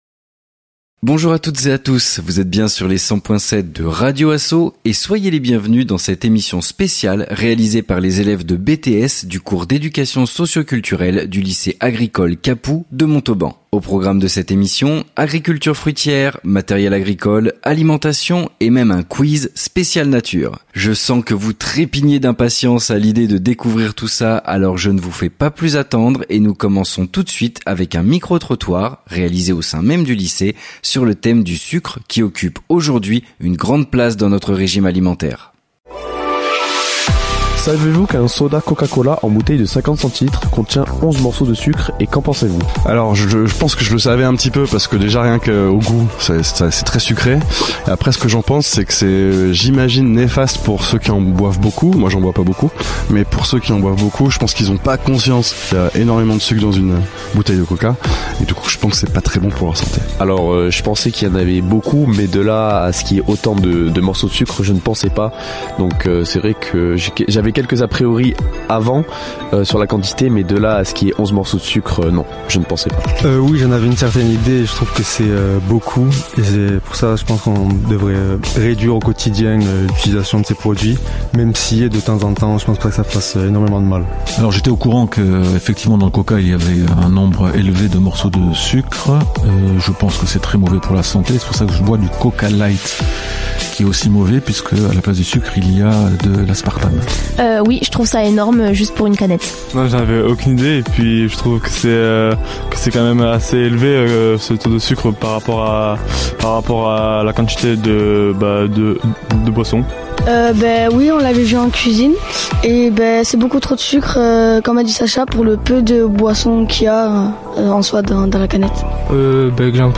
Les étudiants de BTS 2e année ont ainsi réalisé trois interviews, un micro-trottoir et un quiz, au fil des ateliers avec intervenants. L'émission a été diffusée sur les ondes sur 100.7 les 7 et 8 mai derniers.